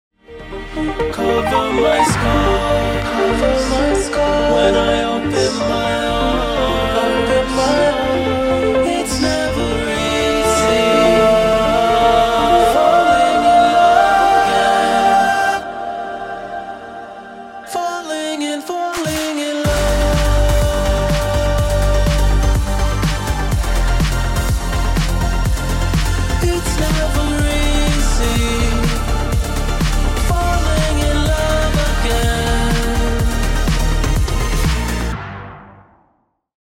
With Some B.Vocals You Didn't Notice